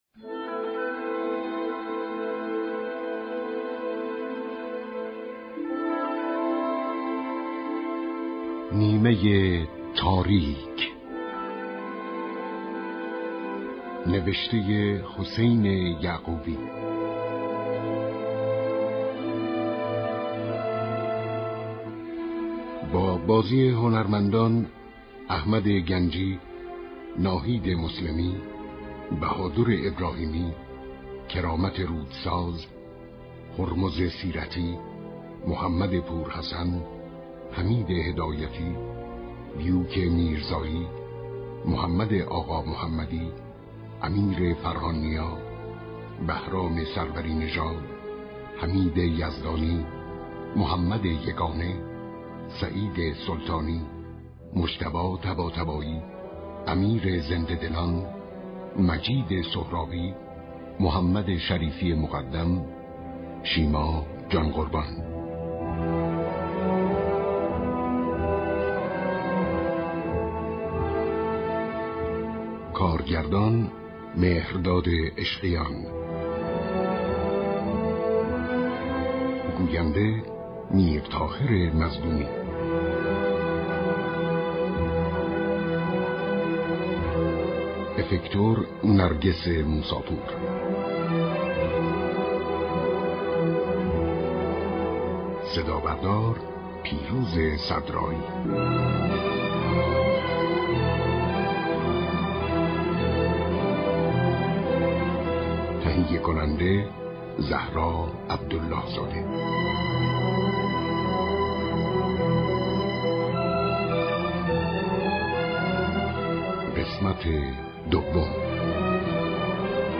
چهارشنبه 28 آذرماه ، شنونده نمایش رادیویی